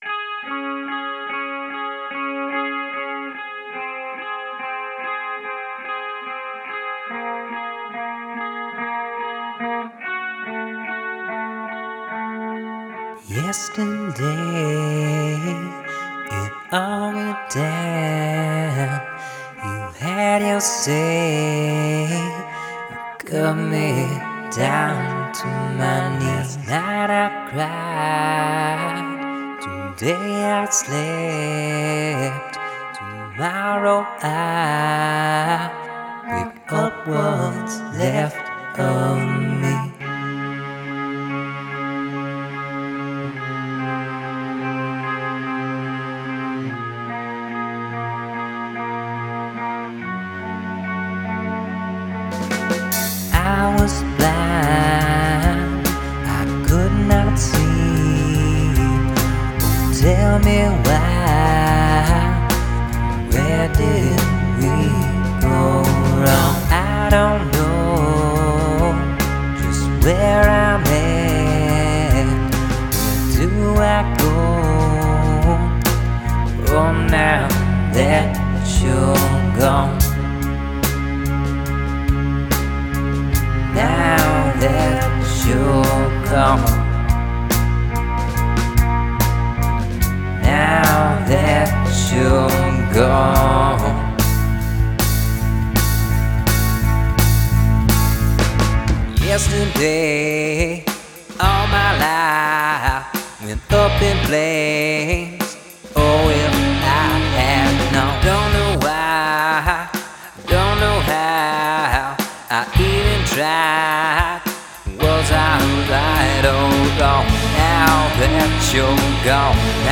Genre: poprock.